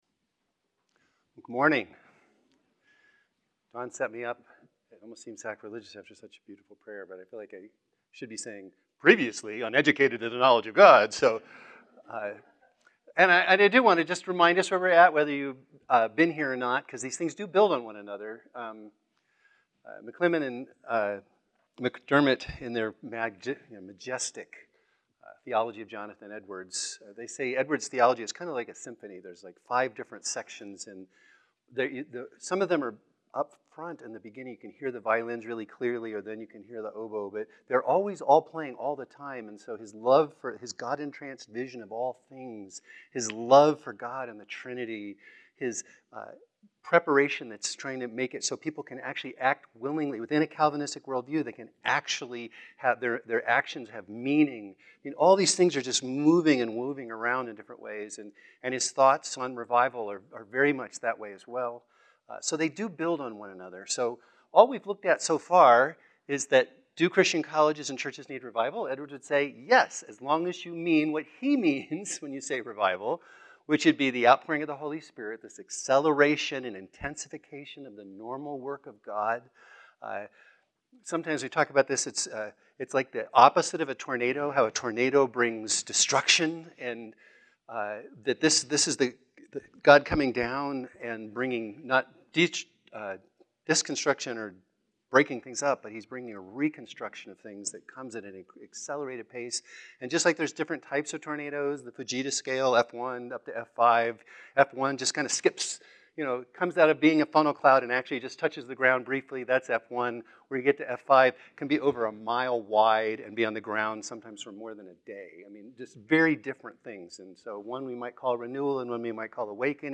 Series: Dr. Garry Owens M.D. Memorial Academic Lectures | Educated to the Knowledge of Christ